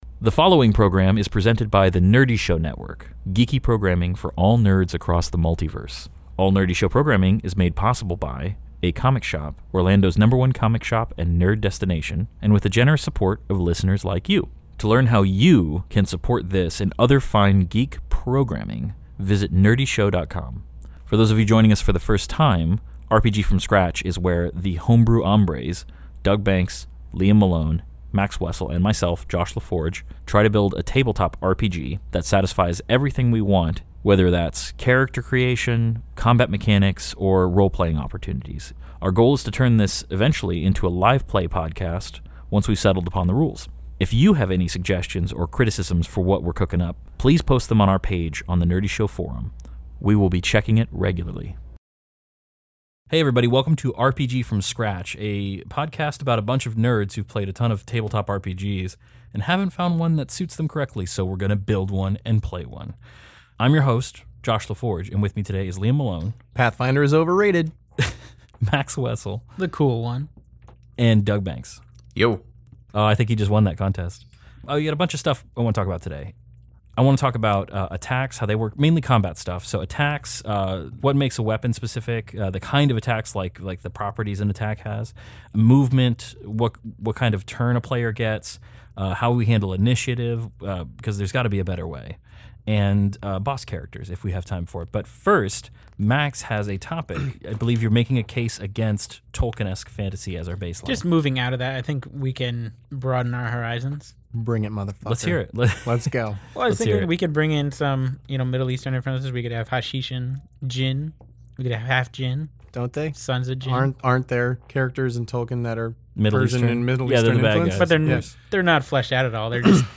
Listen in as as four homebrew hombres design, test, and rebalance their own RPG From Scratch.